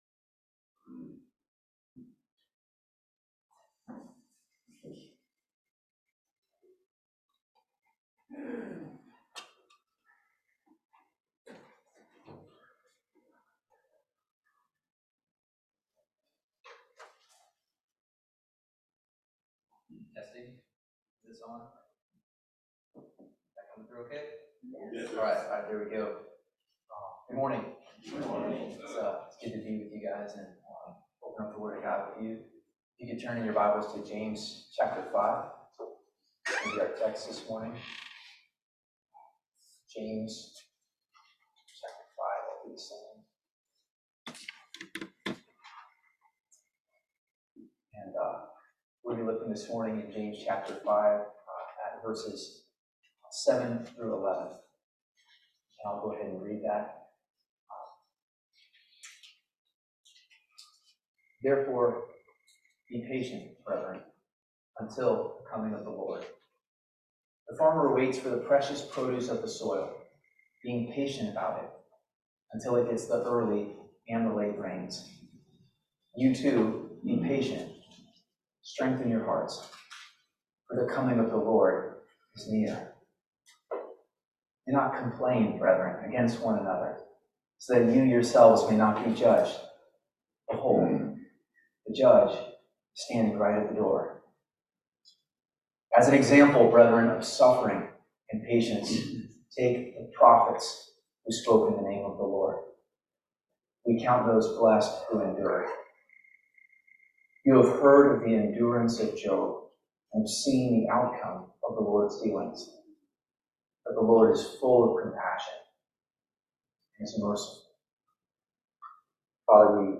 Teaching from James 5:7-11
Service Type: Family Bible Hour